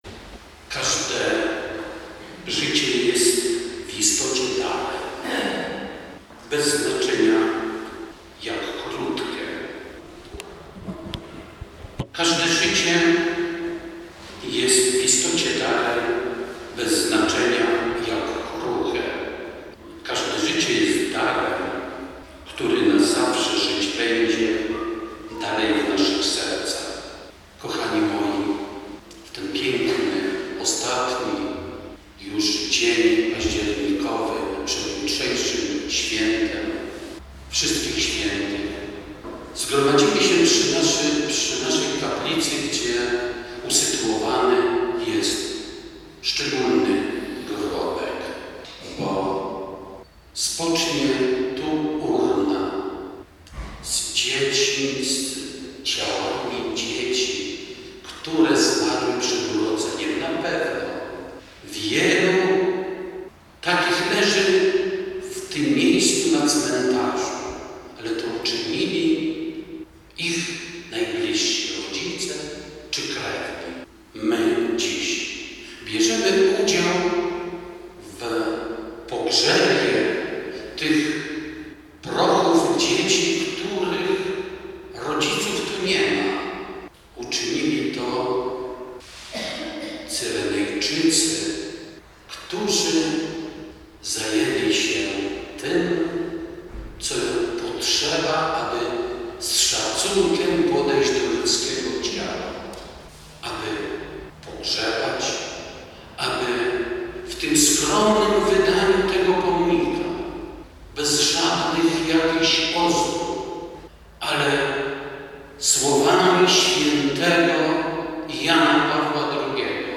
W przededniu Święta Zmarłych na makowskim cmentarzu parafialnym odbył się pogrzeb szóstki dzieci, które zmarły przed urodzeniem.